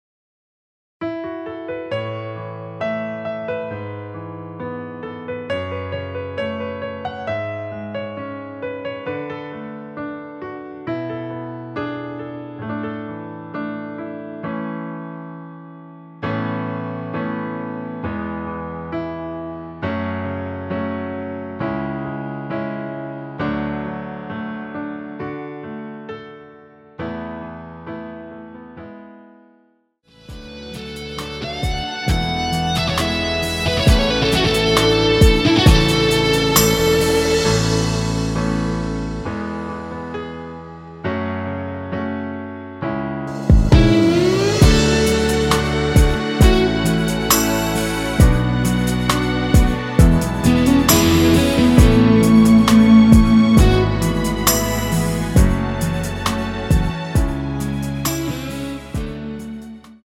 엔딩이 페이드 아웃이라 노래 하시기 좋게 엔딩을 만들어 놓았습니다.
◈ 곡명 옆 (-1)은 반음 내림, (+1)은 반음 올림 입니다.
mr퀄리티 너무 좋아요!
앞부분30초, 뒷부분30초씩 편집해서 올려 드리고 있습니다.